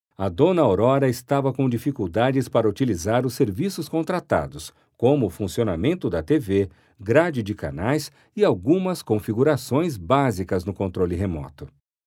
Commercial, Natural, Reliable, Friendly, Corporate
E-learning